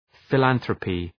Προφορά
{fı’lænɵrəpı}